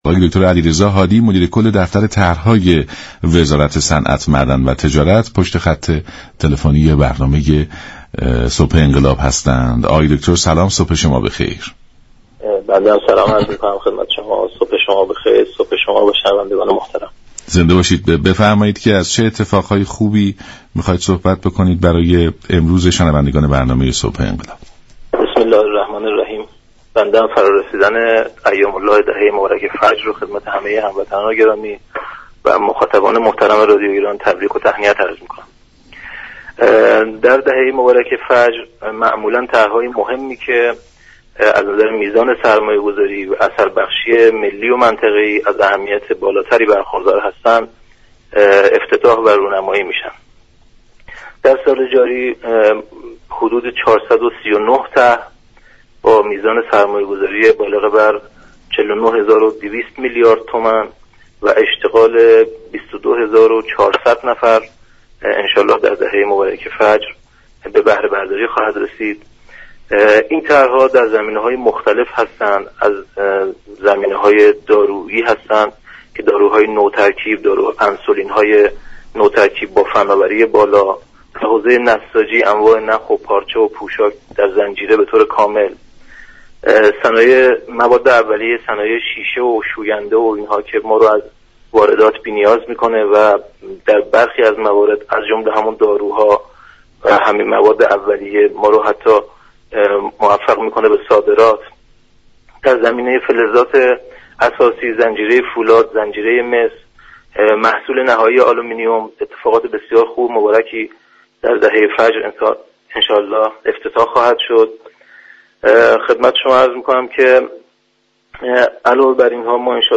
دریافت فایل به گزارش شبكه رادیویی ایران، «علیرضا هادی» مدیر كل طرح های وزارت صنعت، معدن و تجارت در برنامه «صبح انقلاب» درباره طرح های دهه فجر گفت: در دهه مبارك فجر دولت طرح های مهمی كه به لحاظ میزان سرمایه گذاری و اثر بخشی ملی و منطقه ای دارای اهمیت بالایی هستند ، افتتاح و راه اندازی می كند.